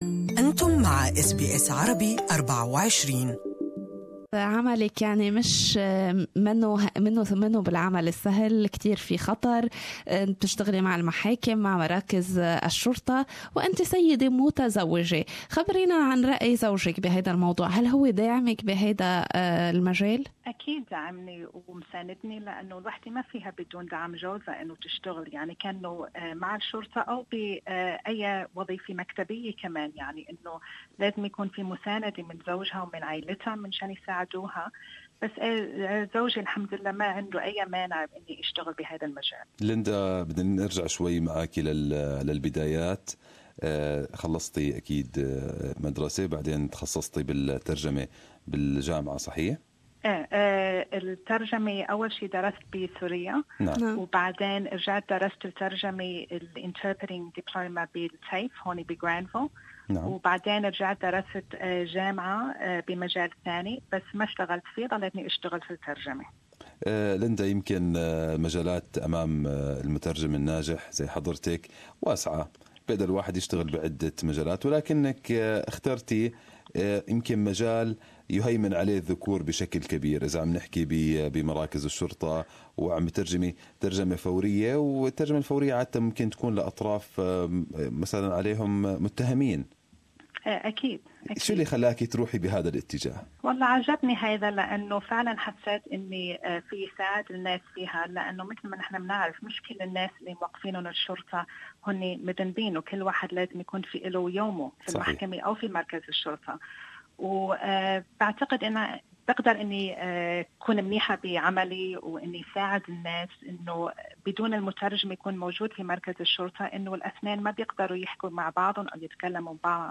Murder, rape and irregular working hours.. An interpreter talks about working with police